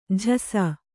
♪ jhasa